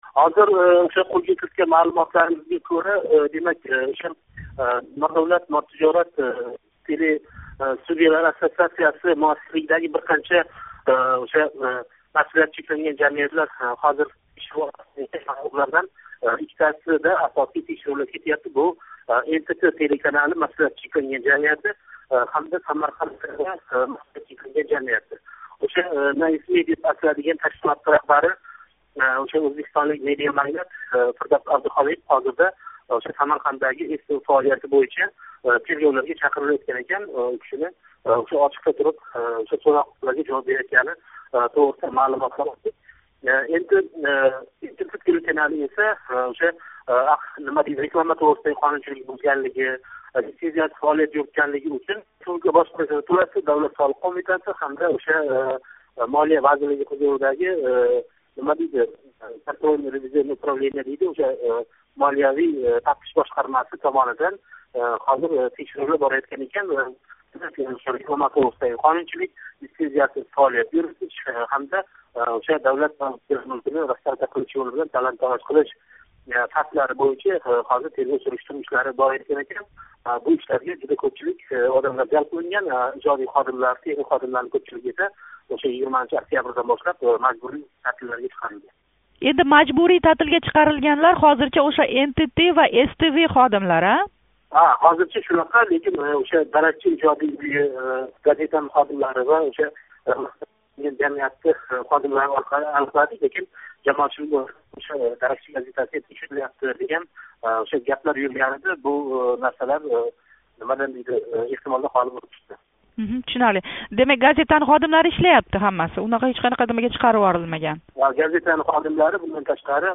суҳбат.